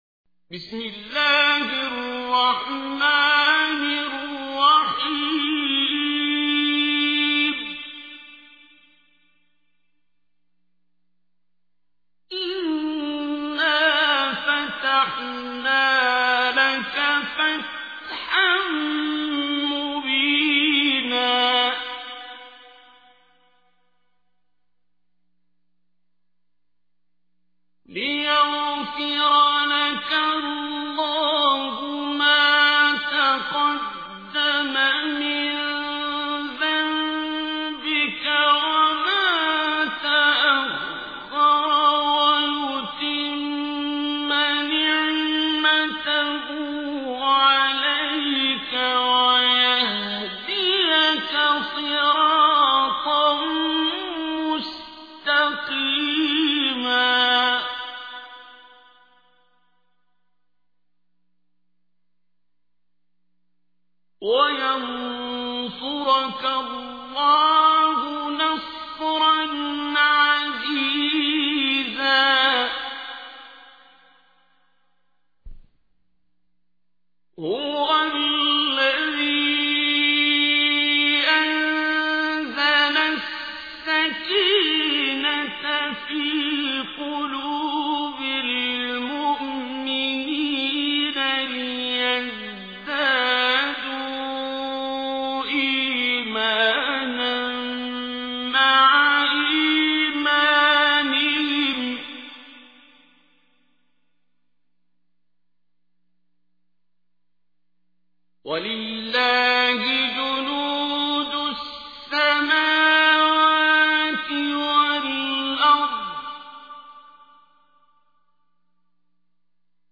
Listen online and download mp3 tilawat/ recitation of Surah Al Fath in the voice of Qari Abdul Basit As Samad